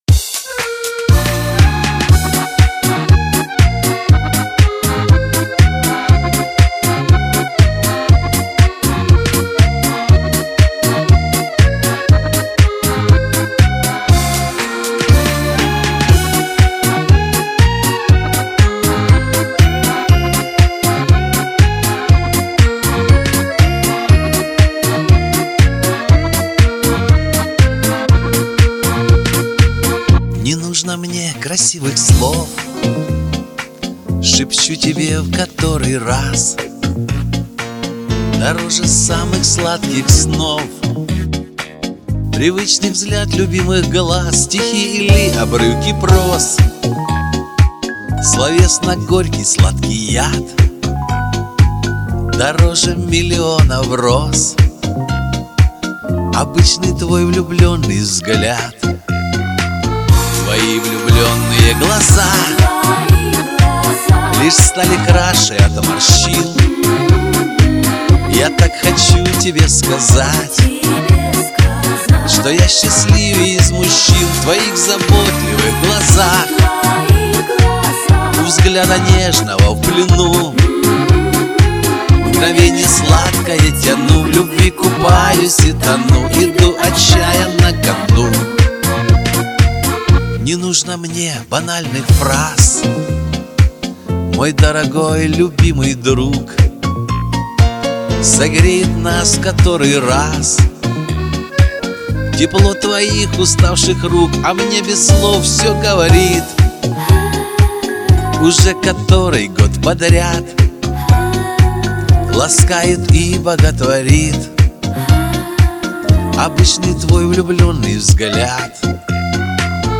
все интонации на месте